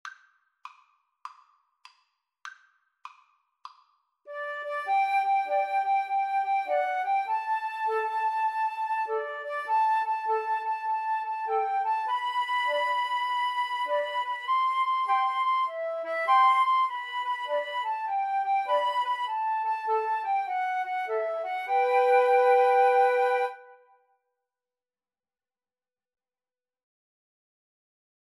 Free Sheet music for Flute Trio
G major (Sounding Pitch) (View more G major Music for Flute Trio )
With a swing!